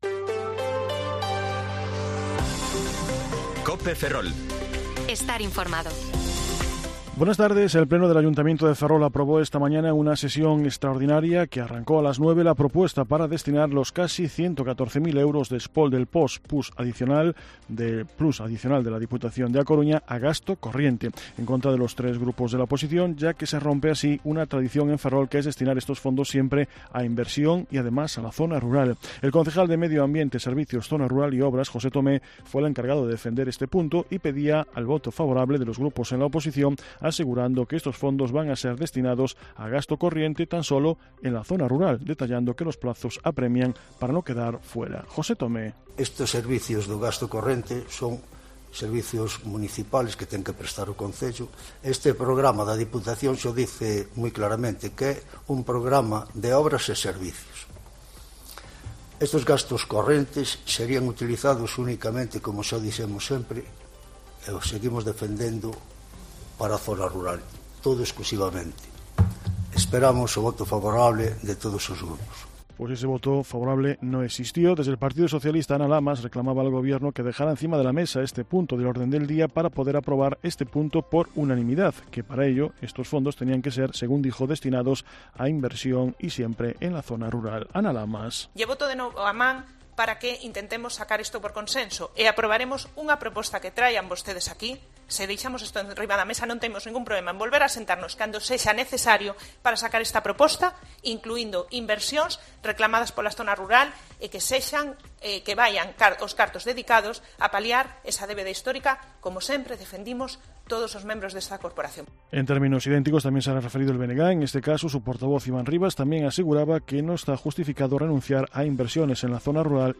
Informativo Mediodía COPE Ferrol 14/9/2023 (De 14,20 a 14,30 horas)